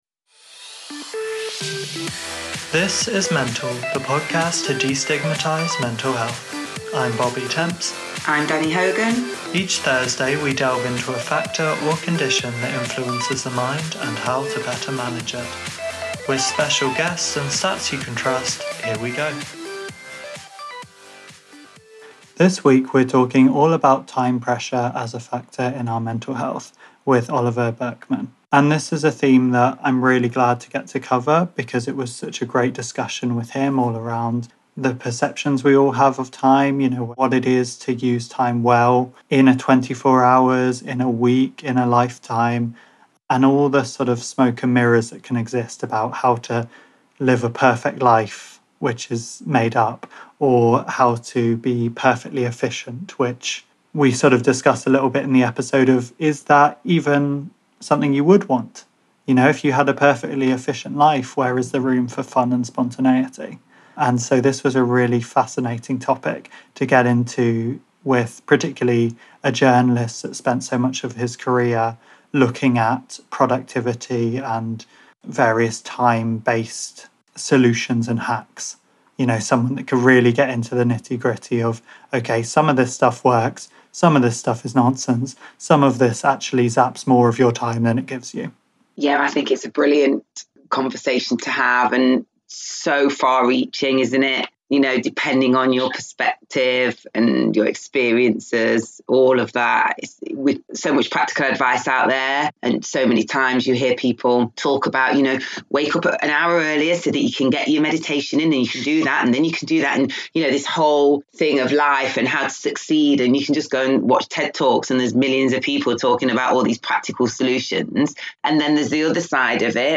Please join us for this uplifting conversation with Oliver Burkeman around what happens we stop focusing on having a perfectly time efficient life and explore all that we actually can achieve instead.